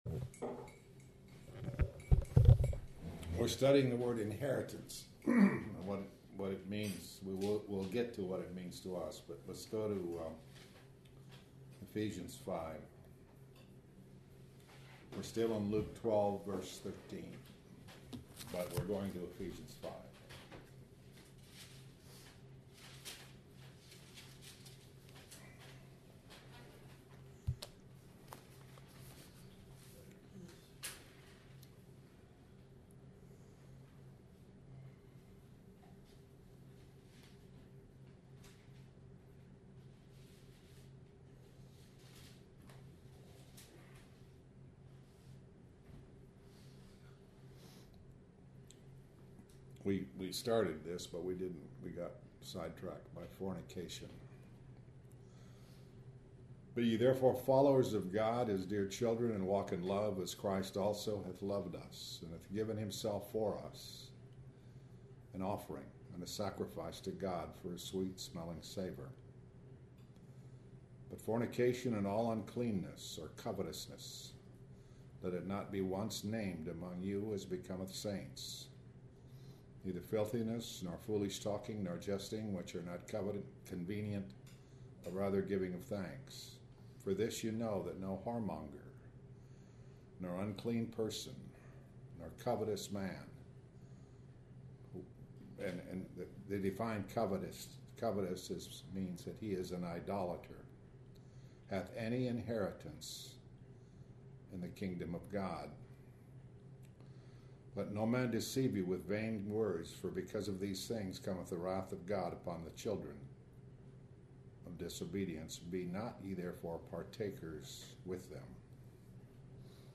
Dec. 10, 2013 – Luke 12:16 Posted on February 25, 2014 by admin Dec. 10, 2013 – Luke 12:16 Ephesians 5:1-8 Col. 3:23 Opportunities for repentance The meaning of “success.” This entry was posted in Morning Bible Studies .